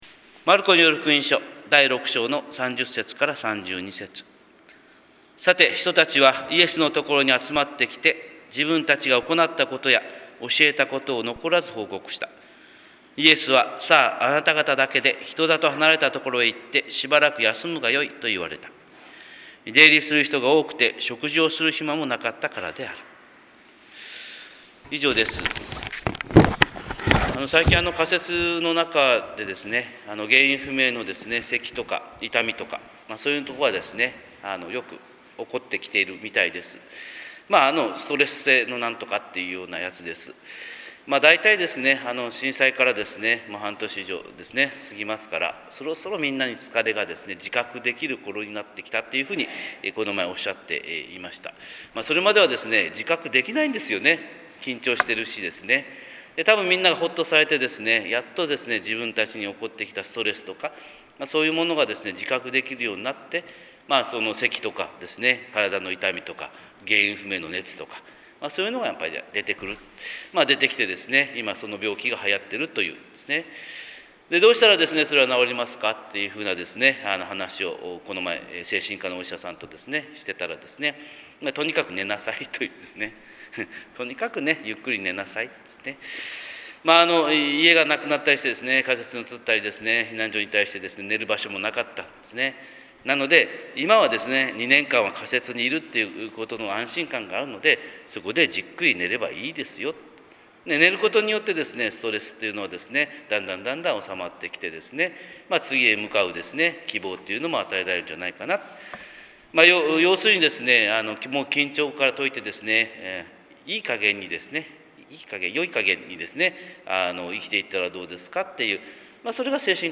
神様の色鉛筆（音声説教）: 朝礼拝170112「休む・憩い」
朝礼拝170112「休む・憩い」